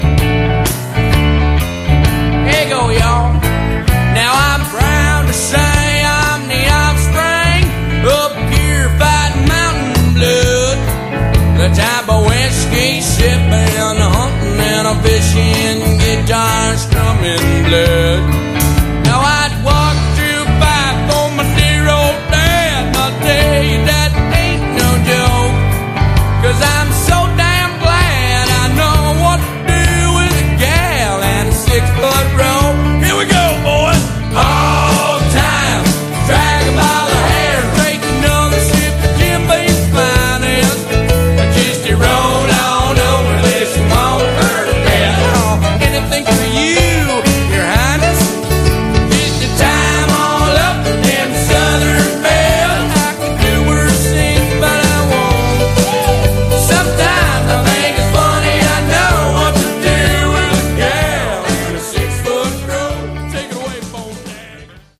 Category: Hard Rock
lead vocals, harmonica
guitar, backing vocals
bass, backing vocals
drums, backing vocals